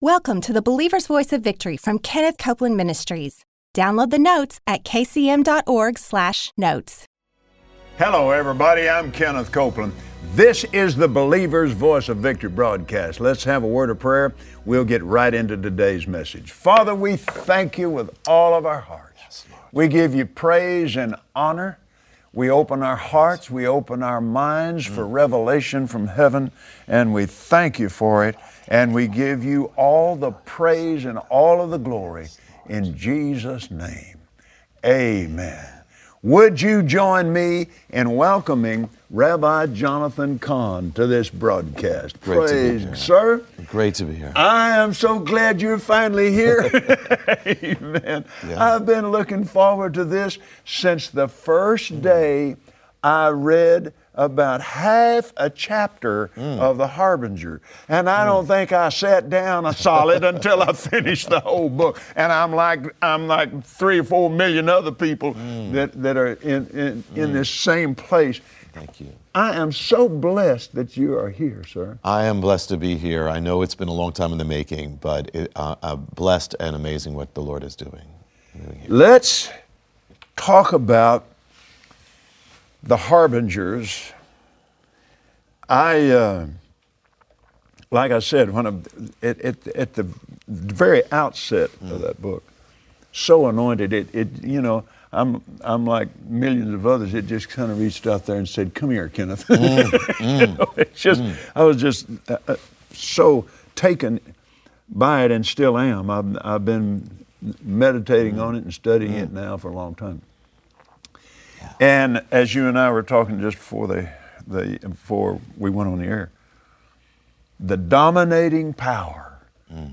Join Kenneth Copeland and special guest, Rabbi Jonathan Cahn, on the Believer’s Voice of Victory, and learn of the love and grace of our Heavenly Father.